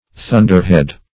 Thunderhead \Thun"der*head`\, n.